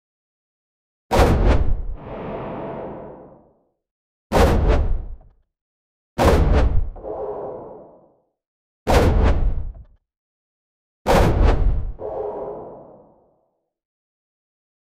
Audio-transitions Sound Effects - Free AI Generator & Downloads
Buildup riser sound effects which is used in hooks of the video 0:05 There are gentle pieces of music, and/or intense scores, depending on the variation and appearance of each bumper. A couple of whooshing sound effects are also present. 0:15